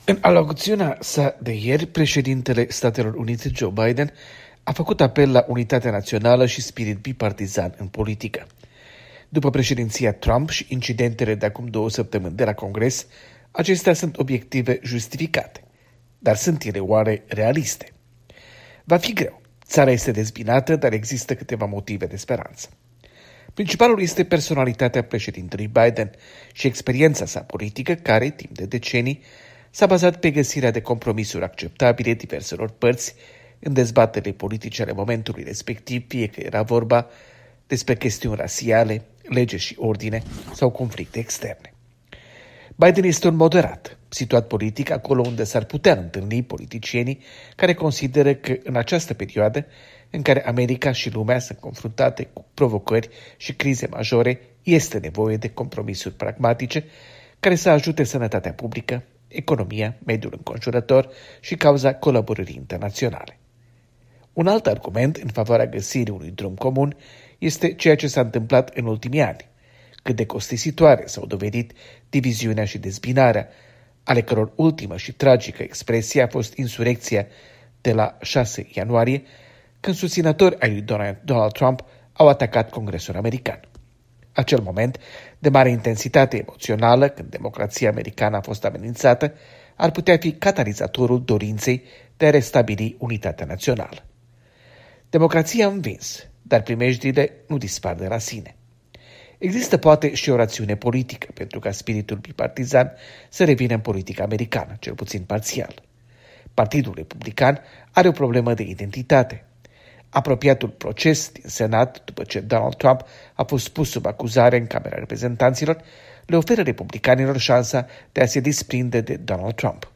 Corespondență de la Washington